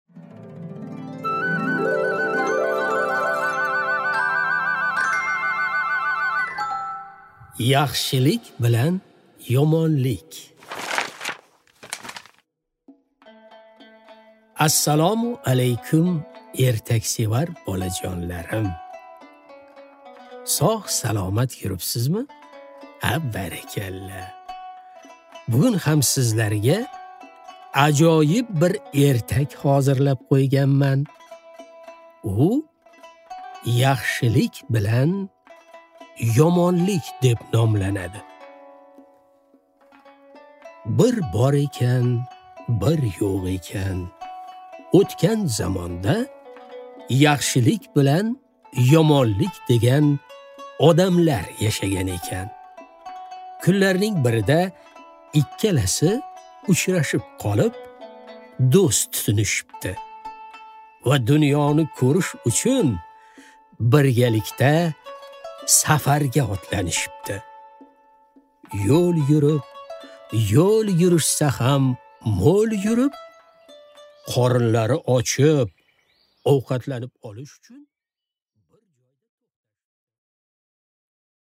Аудиокнига Yaxshilik bilan Yomonlik